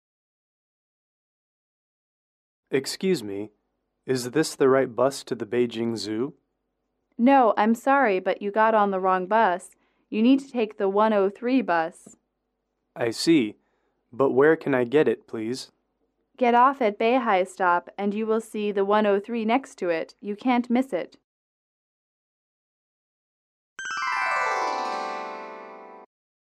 英语口语情景短对话31-1：乘错车(MP3)